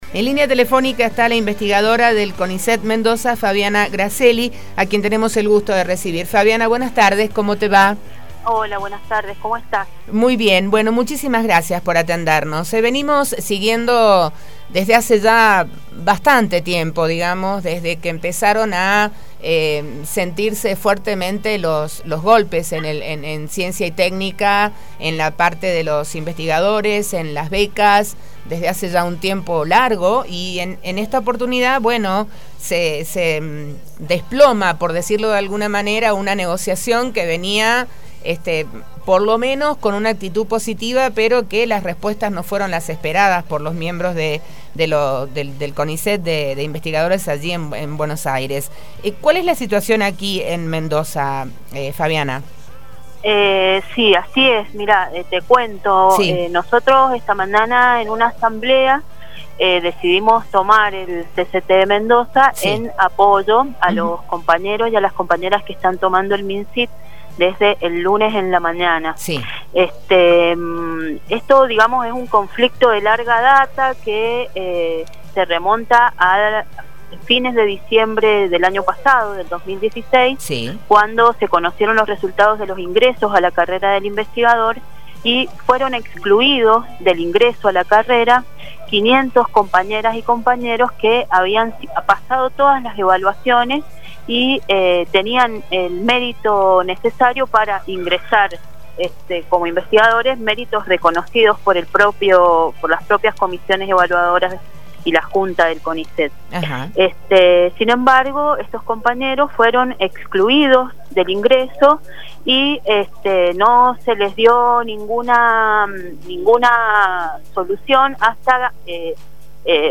diálogo con La Posta, la investigadora